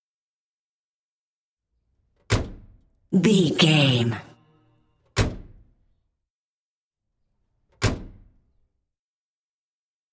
Car hood close
Sound Effects